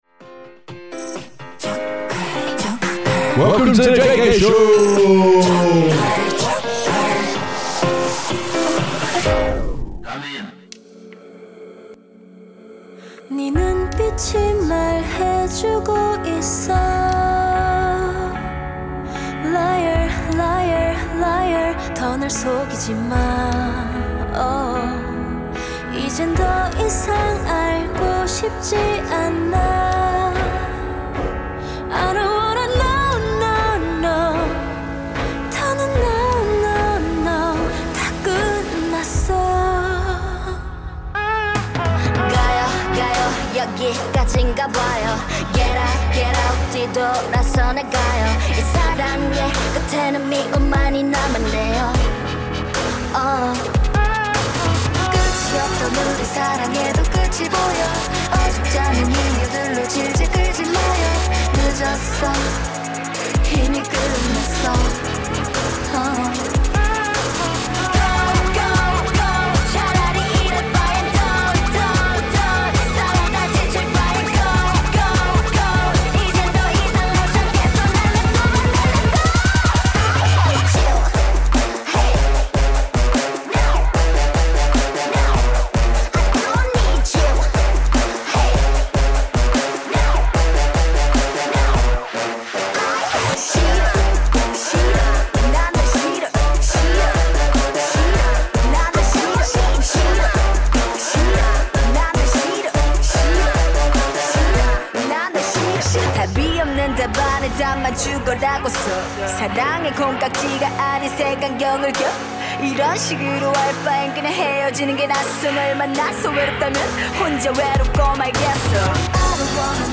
Warning: There is some swearing between 49mins and 53mins regarding CL’s song.